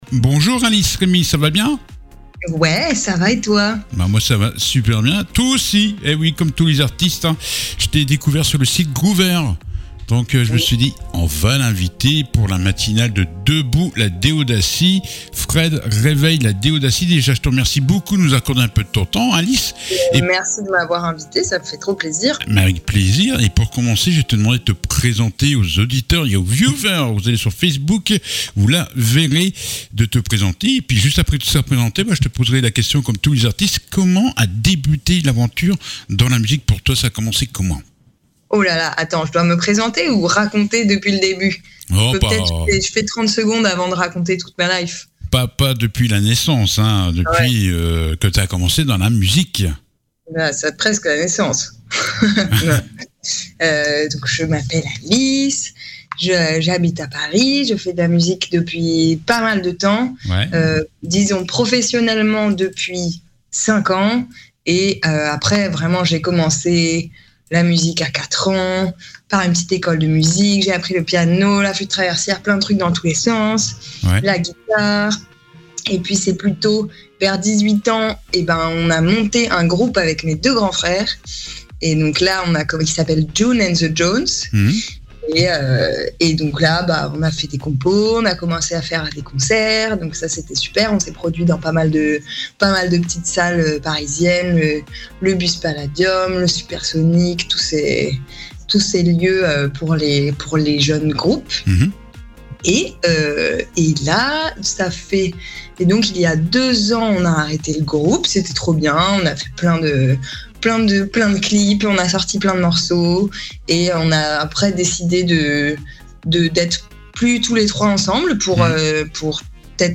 L'interview du jour